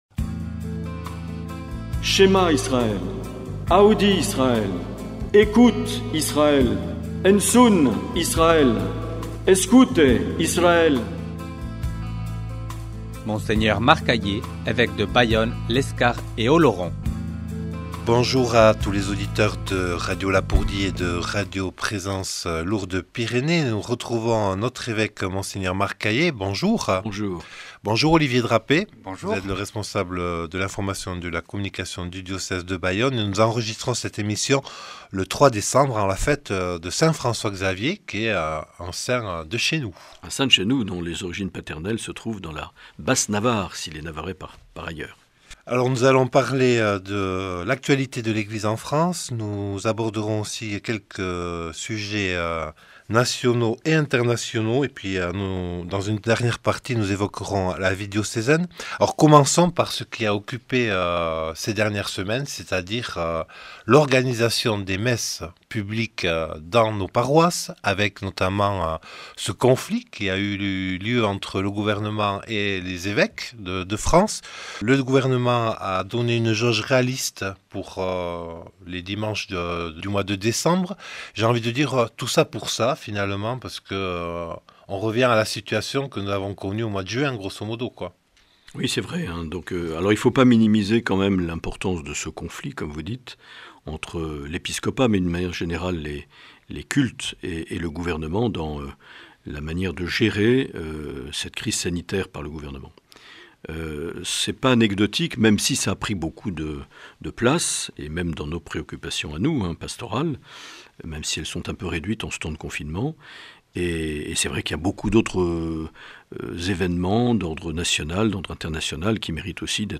Dans cet entretien enregistré le 3 décembre 2020, Mgr Aillet revient sur la question de la liberté de culte, sur l’Assemblée plénière des évêques du 3 au 8 novembre, sur la gestion de la crise sanitaire par les autorités et les restrictions de liberté qui en découlent.